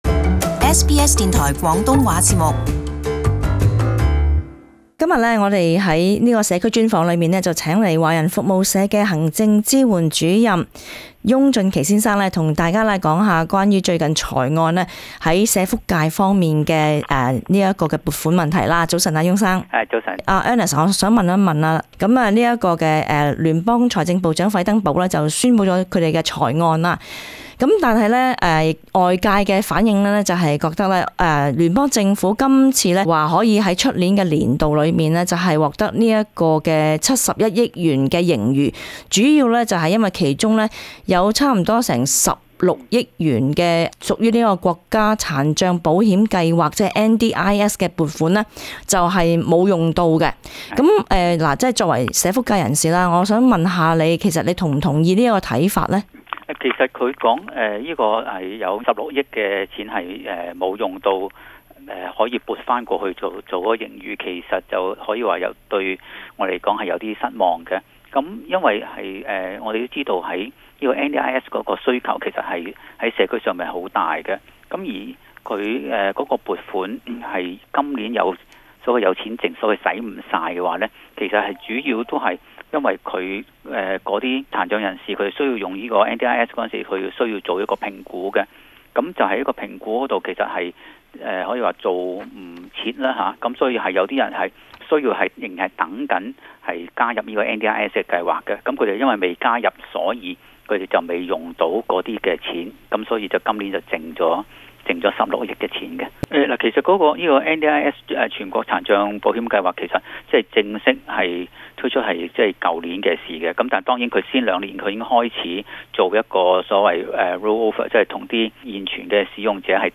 【社區專訪 】犧牲國家殘障計劃達致財政盈餘？